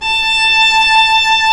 Index of /90_sSampleCDs/Roland L-CD702/VOL-1/STR_Viola Solo/STR_Vla1 % marc